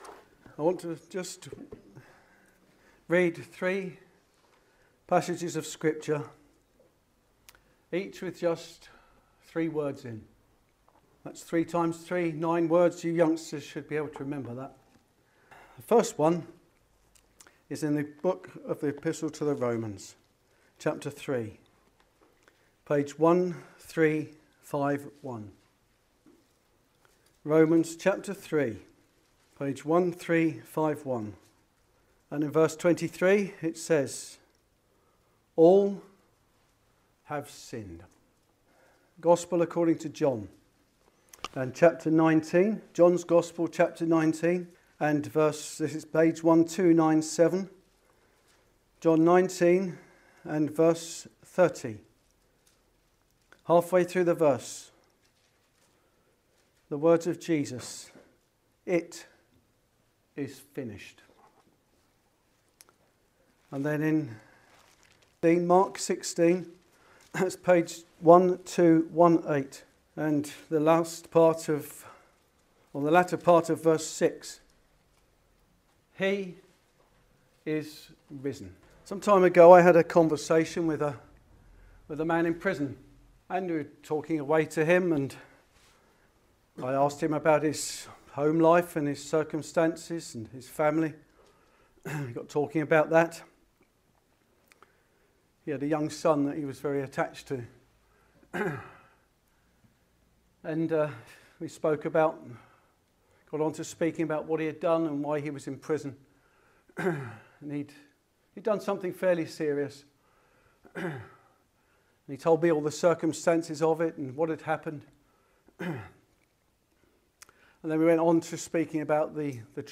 In this gospel preaching, we will explore three sets of three words that reveal themes of redemption, completion, and resurrection.